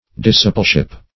Discipleship \Dis*ci"ple*ship\, n.